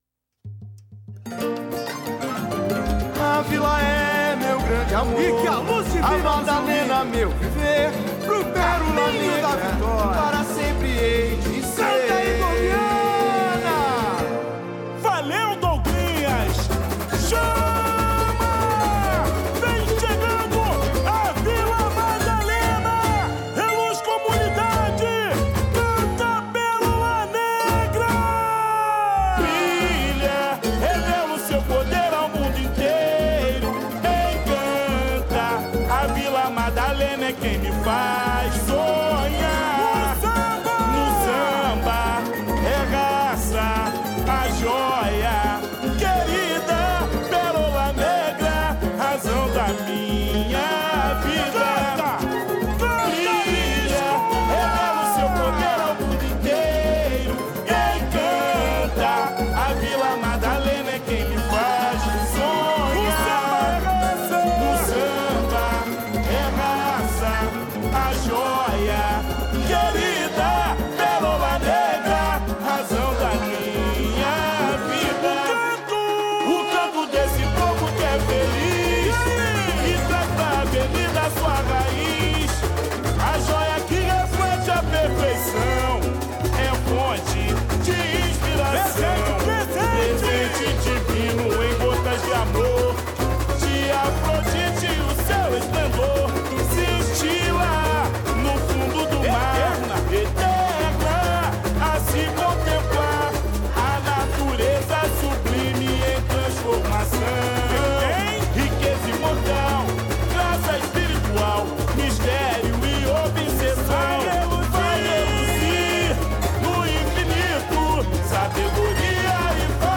Intérprete: